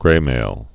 (grāmāl)